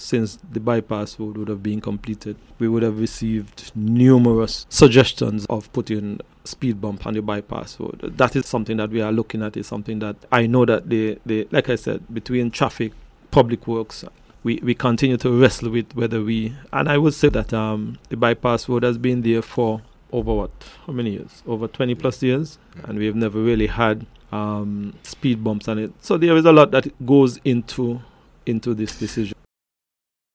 During a panel discussion, Minister of Public Works, Hon Spencer Brand said a number of factors need to be taken into consideration before speed bumps are placed in certain areas on the island: